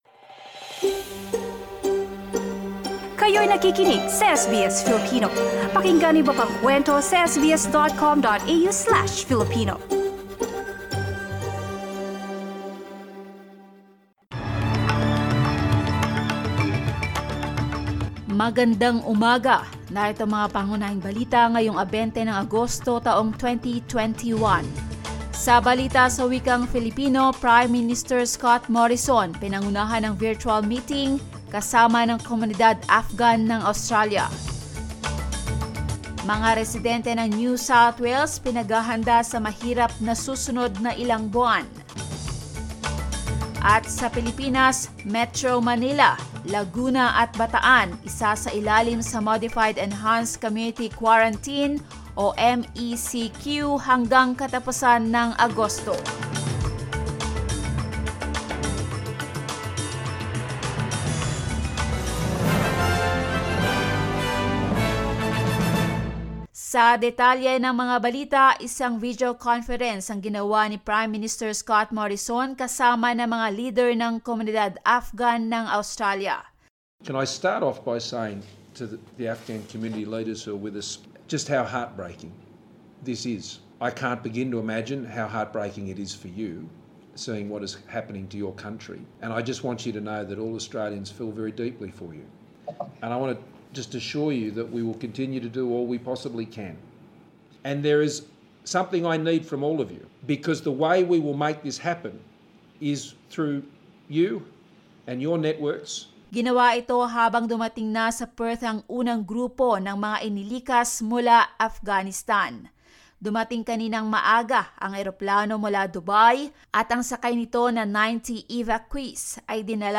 SBS News in Filipino, Friday 20 August